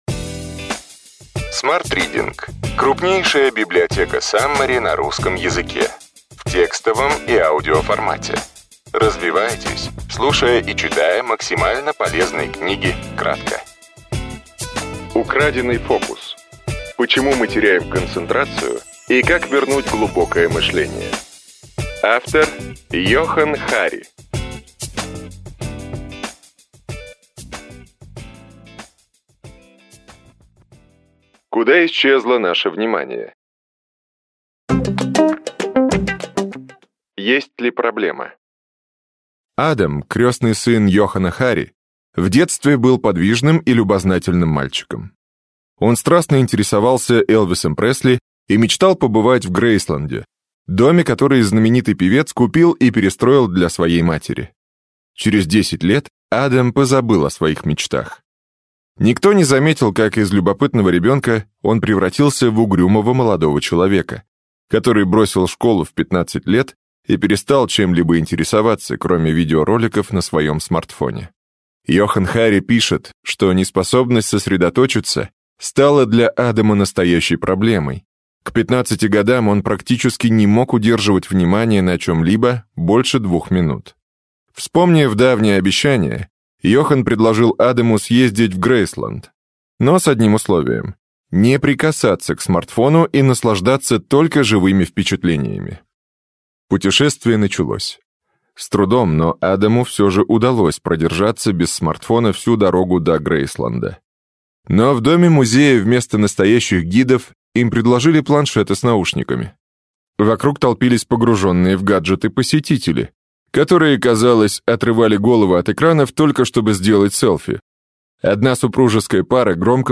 Аудиокнига Украденный фокус. Почему мы теряем концентрацию и как вернуть глубокое мышление. Йоханн Хари. Саммари | Библиотека аудиокниг